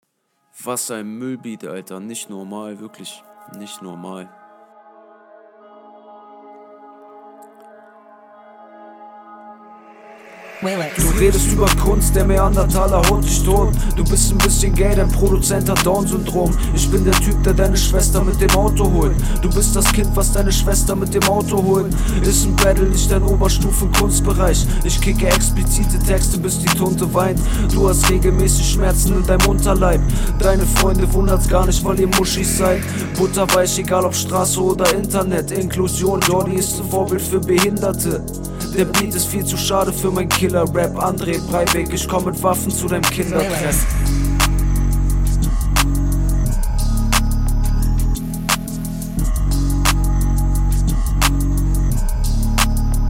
Über den Beat rumheulen kommt immer cool.
Ganz schön kurz, Flow ist aber deutlich mehr on Point als bei deinem Gegner.